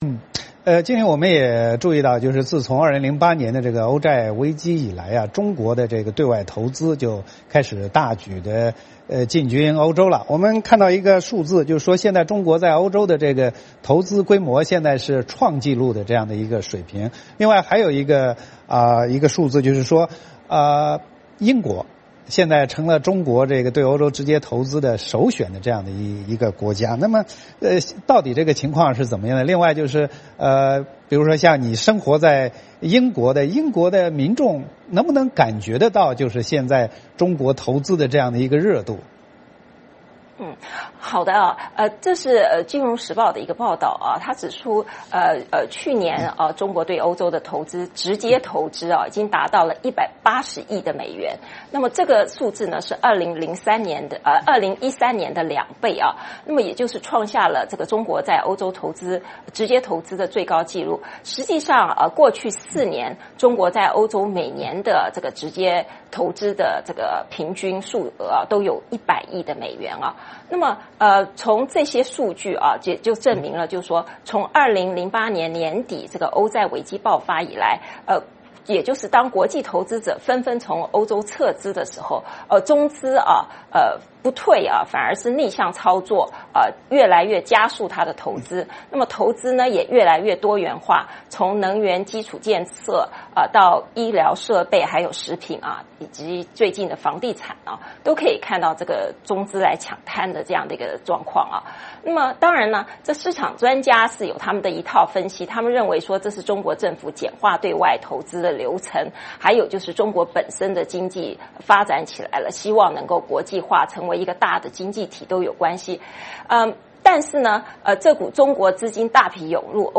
VOA连线：中资进军欧洲，英国成中国投资首选国